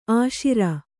♪ āśira